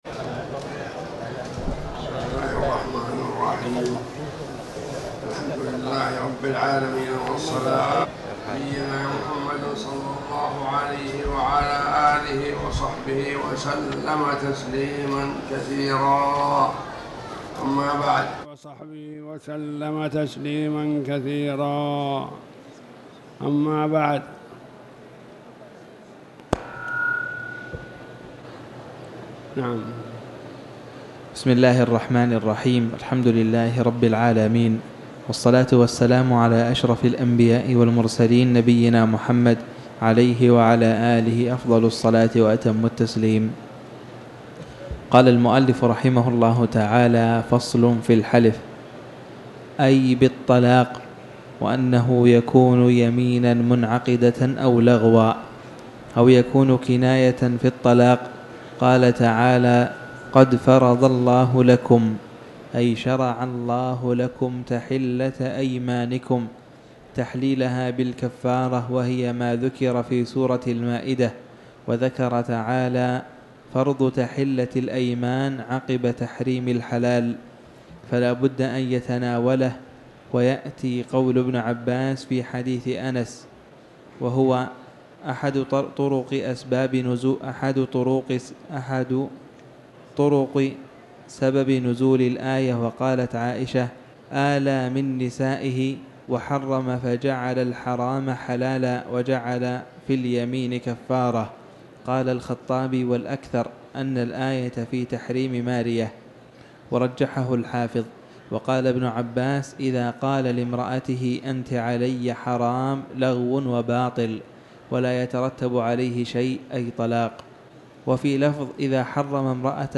تاريخ النشر ٣ ذو الحجة ١٤٤٠ هـ المكان: المسجد الحرام الشيخ